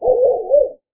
dogspacebark4.mp3